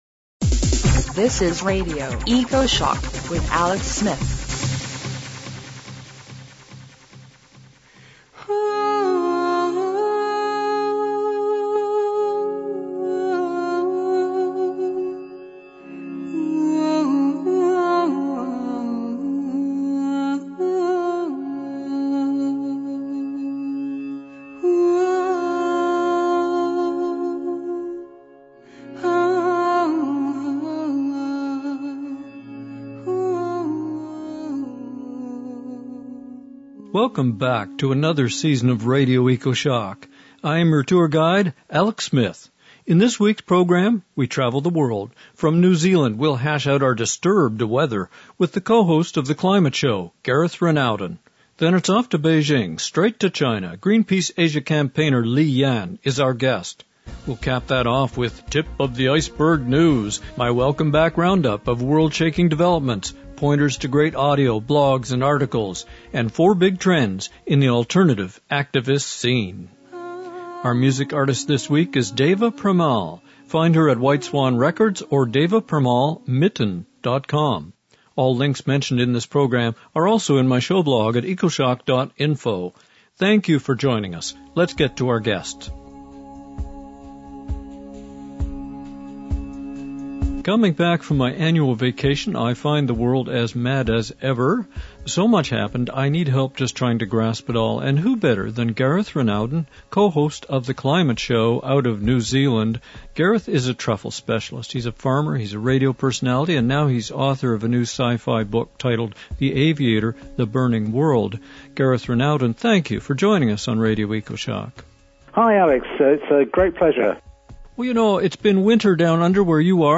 CLIMATE CHANGE IN CHINA Then it's off to Beijing, for a report straight from China.